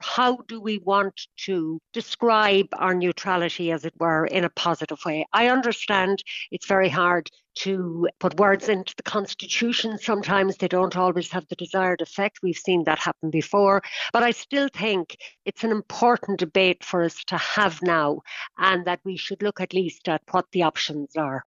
South Donegal Deputy Marian Harkin says many Irish people take great pride in our neutrality and feels that should be reflected in the Constitution: